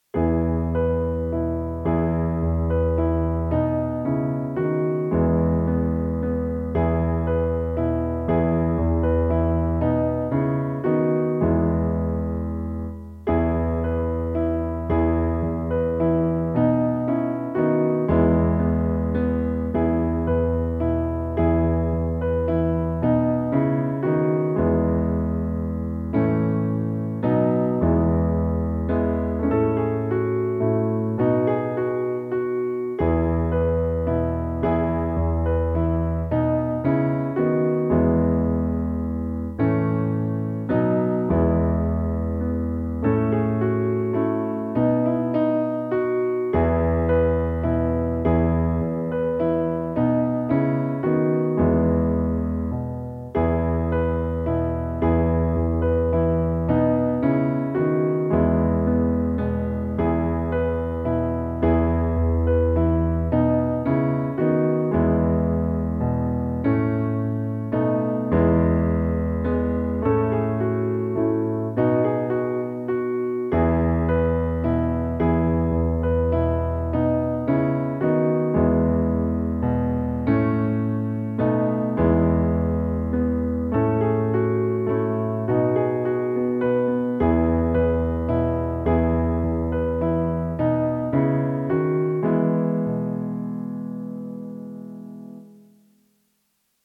zajmena-klavirni-doprovod.mp3